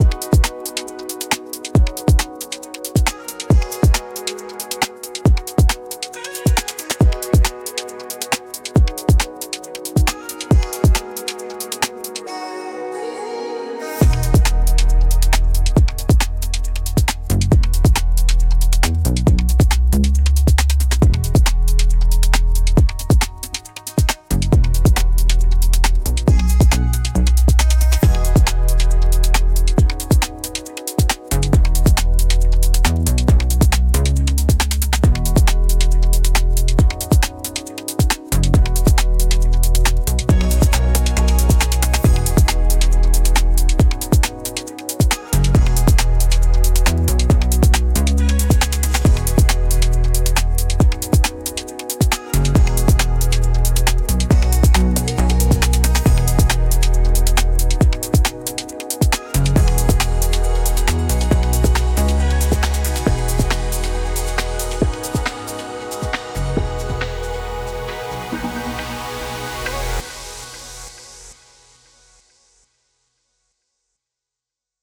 Electronic, Corporate
Groovy, Upbeat
136 BPM